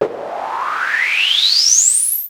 Machine18.wav